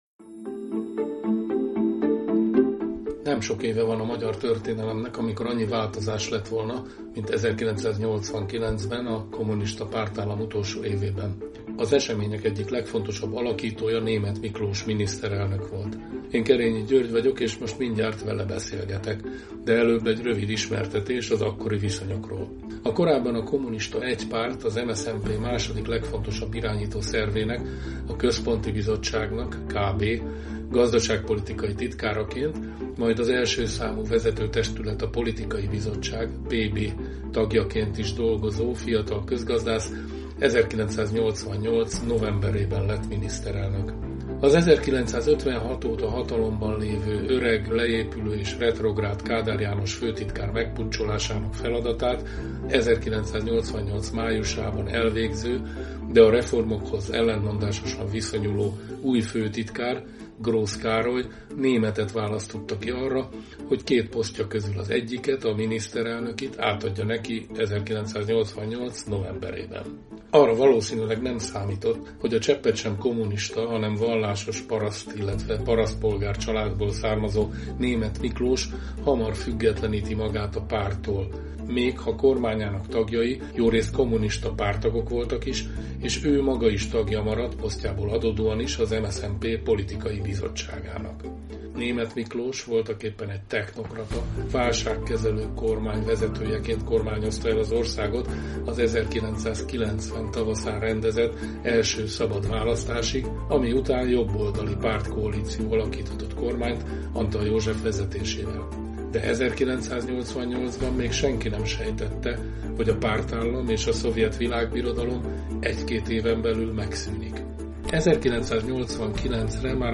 Az események egyik legfontosabb alakítója Németh Miklós miniszterelnök volt. Kétrészes interjúnkban emlékezik vissza az átmenet néhány aspektusára.